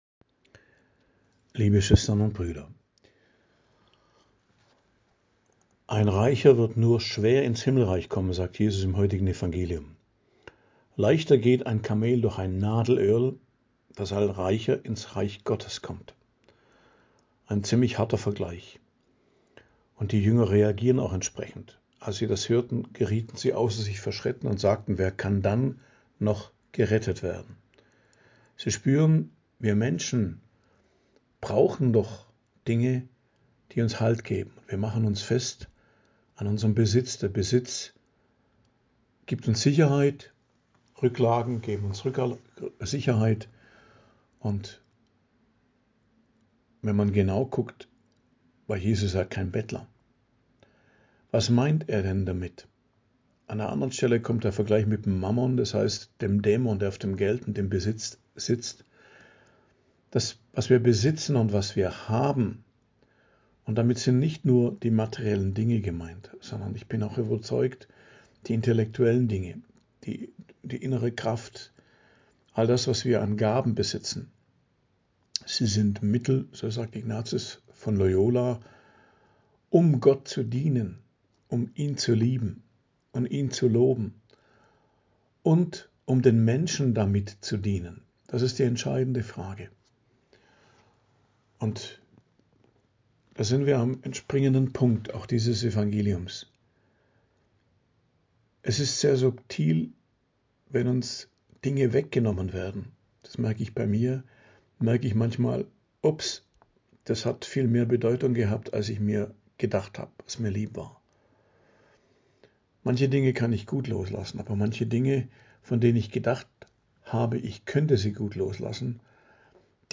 Predigt am Dienstag der 20. Woche i.J., 19.08.2025